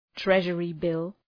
Προφορά